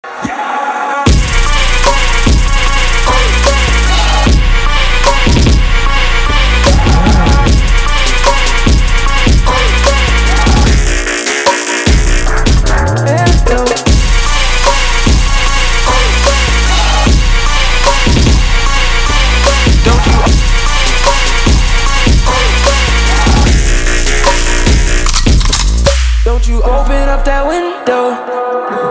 • Качество: 320, Stereo
Trap